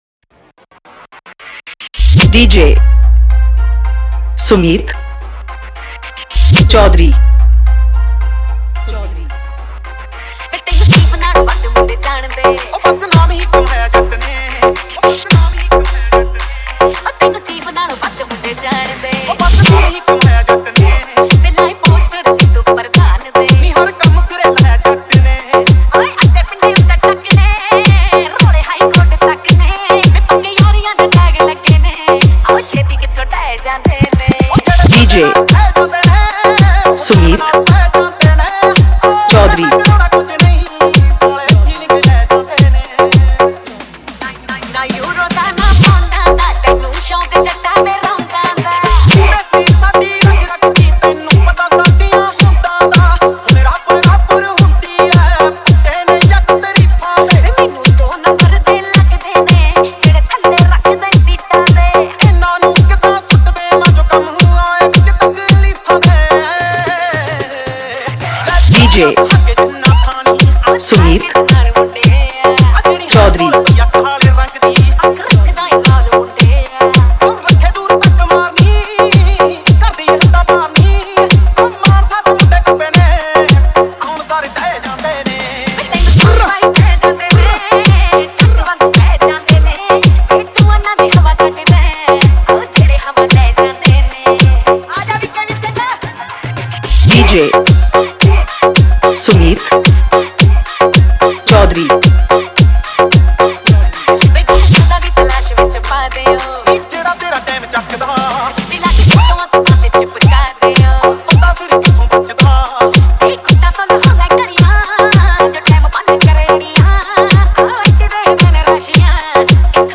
Punjabi Remix Report This File Play Pause Vol + Vol -